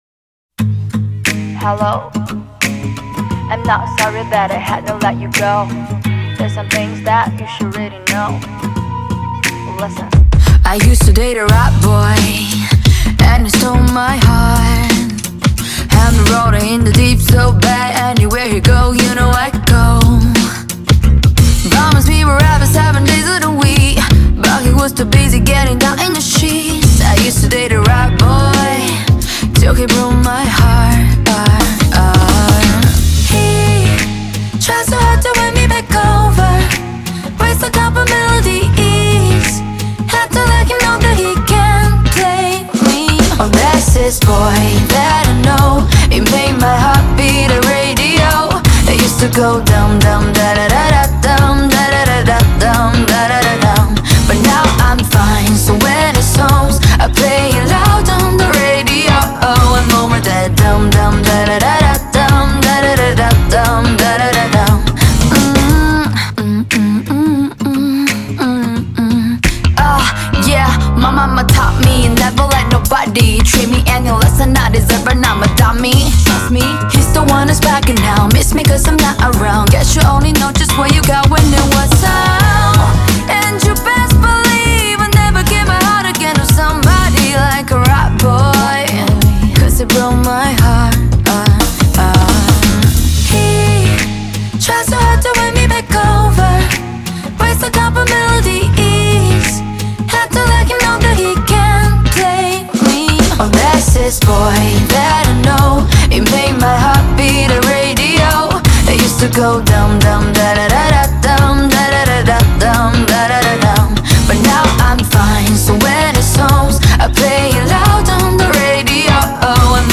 BPM88-88
Audio QualityPerfect (High Quality)
C-Pop song for StepMania, ITGmania, Project Outfox